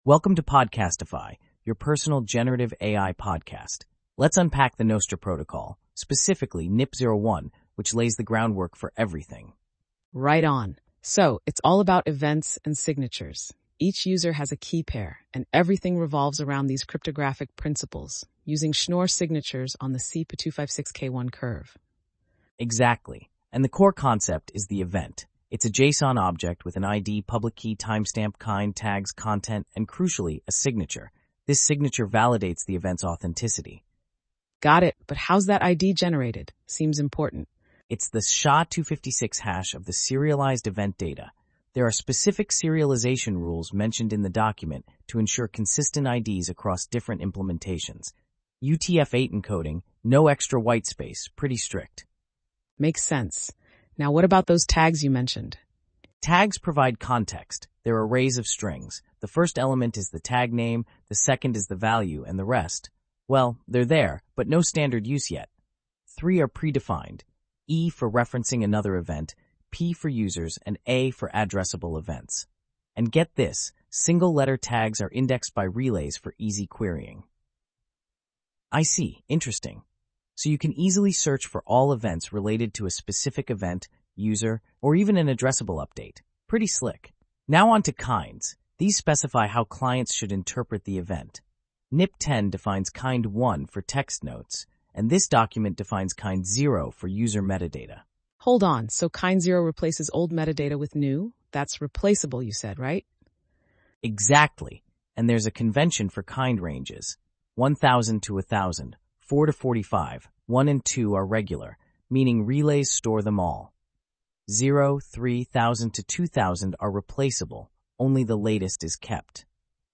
this is a bit different, it will reformat the content into a podcast (two people talking back and forth and going over the content). its not just direct text to speech. its nice for technical papers that are hard to get through.
obviously they win with their voice switch, but I already uploaded mine to apple during the weekend just for a test and I didn't know that python lib existed!